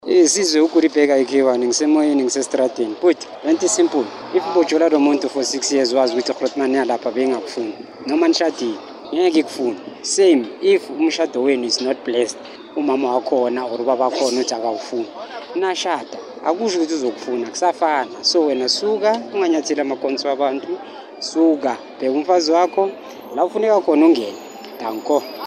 Kaya Drive listeners shared why intervening in your in-laws problems is a bad idea: